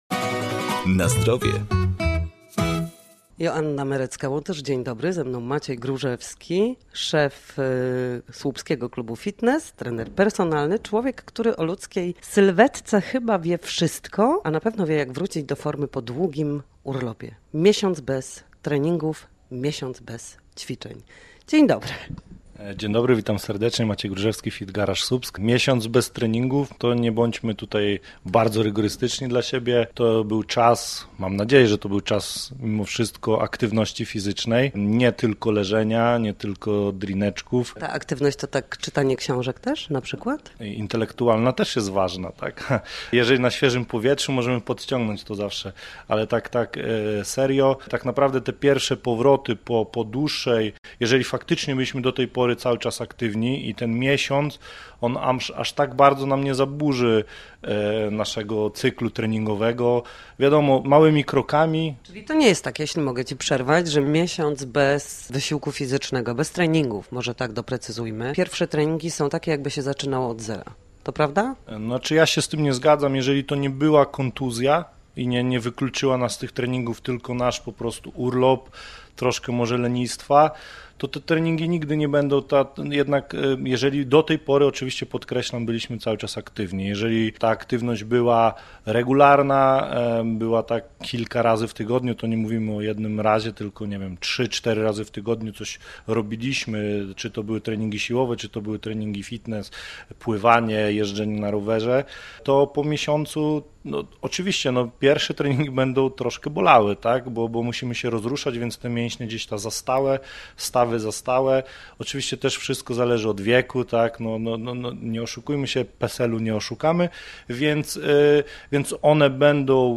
Na antenie Radia Gdańsk mówiliśmy o tym, jak wrócić do aktywności fizycznej po długim urlopie i błogim lenistwie.